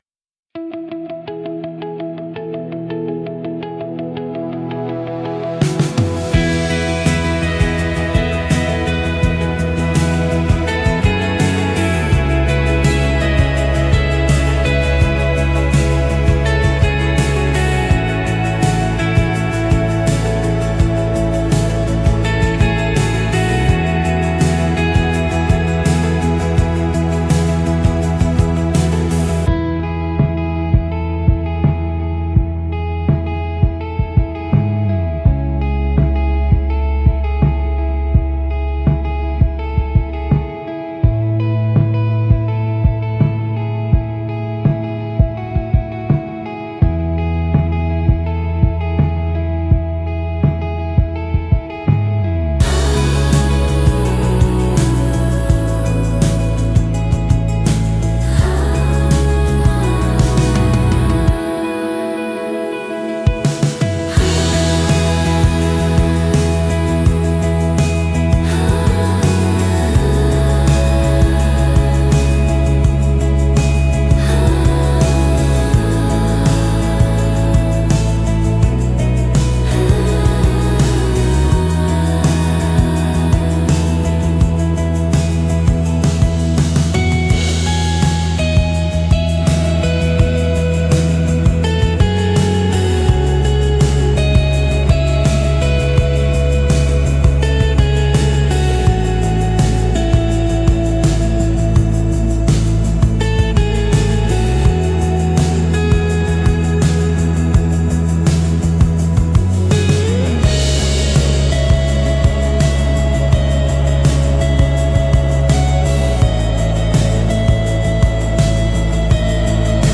静かで美しい音の重なりが心地よく、感性を刺激しながらも邪魔にならない背景音楽として活用されます。